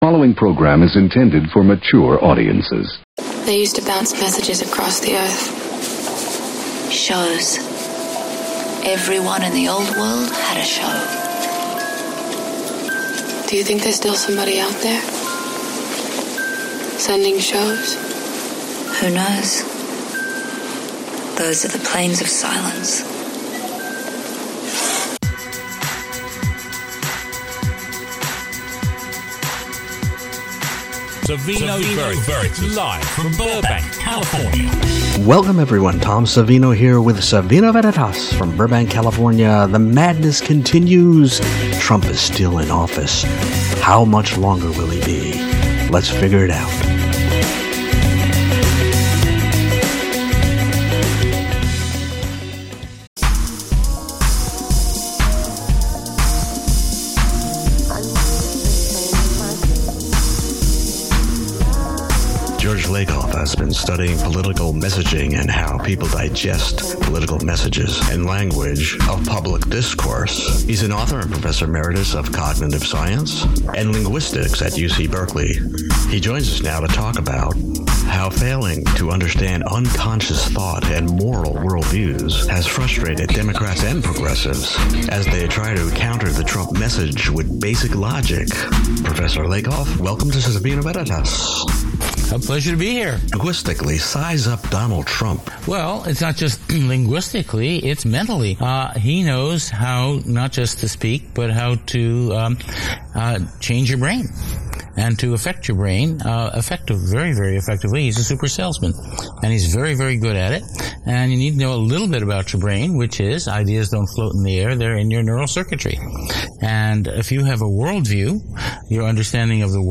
Then, thanks to a listener’s contribution, Veritas airs part of a previously banned Mr. Roger’s Neighborhood episode (one of three) that takes a hard-hitting jab at President Ronald Reagan’s 1983 budget which, (like Trump’s today), guts public broadcasting and the arts in order to fund an unwise military build up. Hear this poignant episode for kids deal with the building of a million bombs to “protect” the Neighborhood.